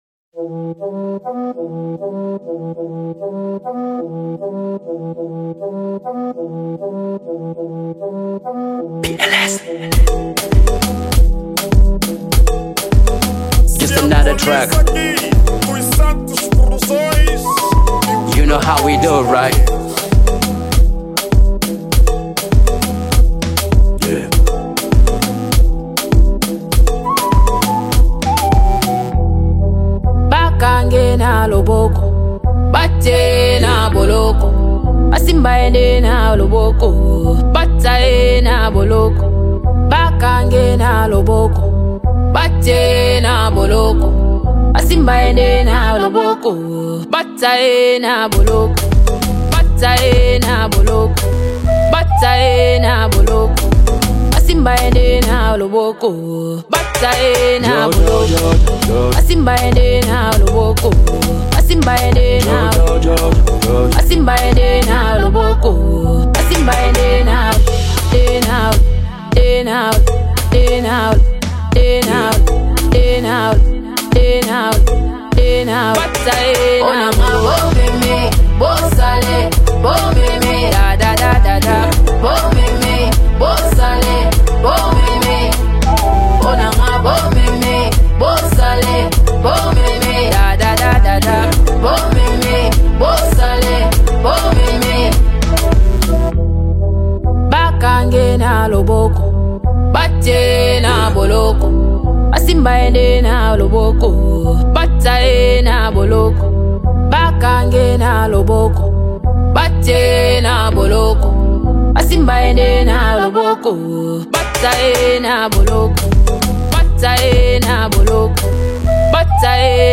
ESTILO DA MÚSICA:  KIZOMBA
MUSIC STYLE: KIZOMBA